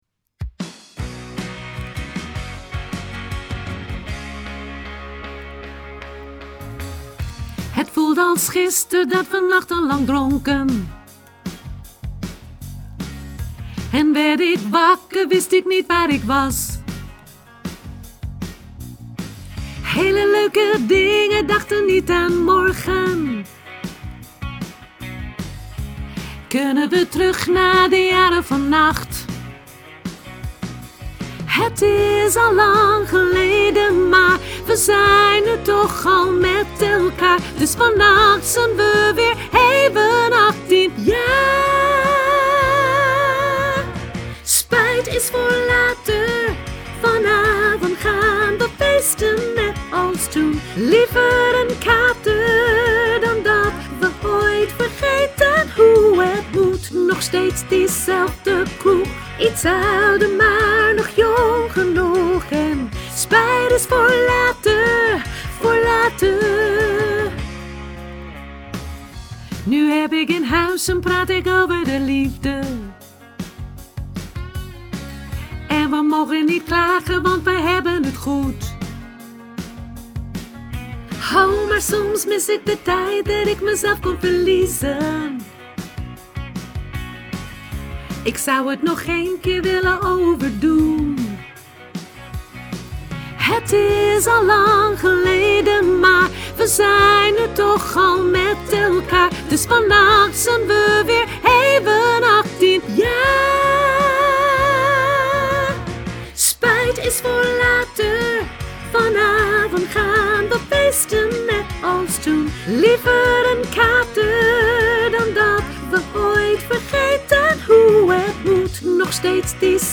sopraan mezzo